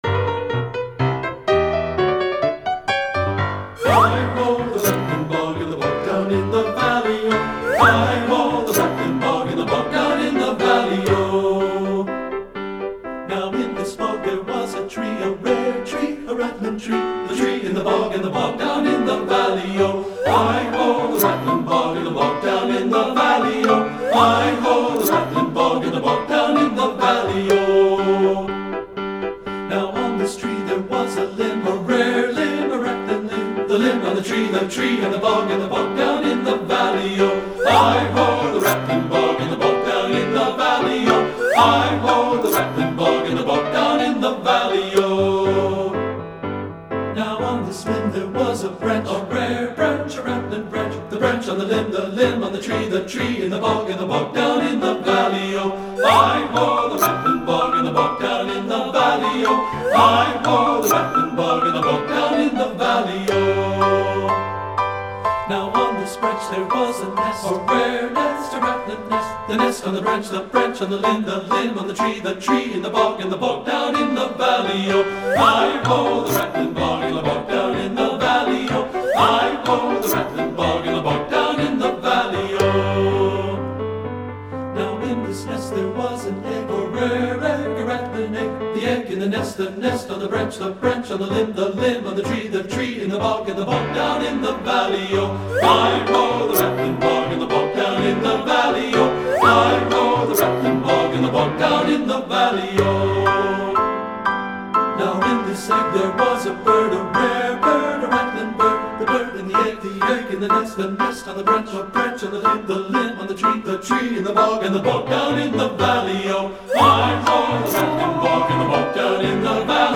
Voicing: TB, Descant and Piano